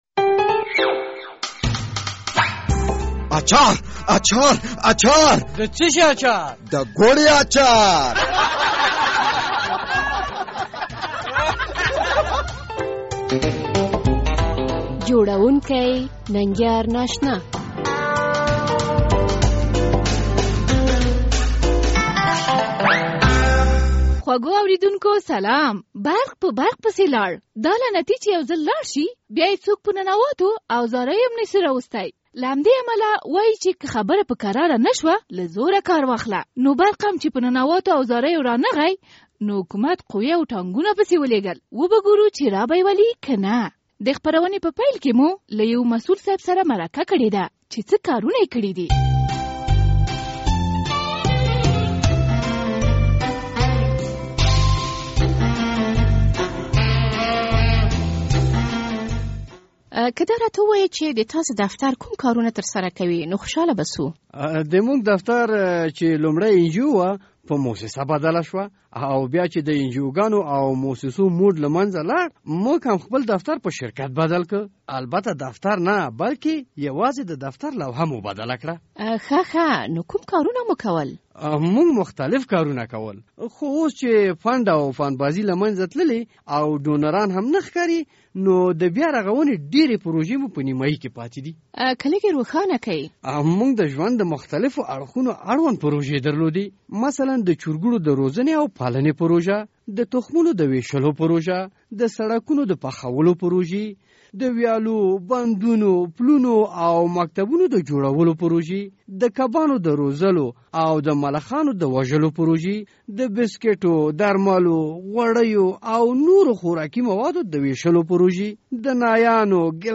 د ګوړې اچارپه دې خپرونه کې به لمړی هغه مرکه واورئ چې د یوه دفتر له مسول سره موکړې ده...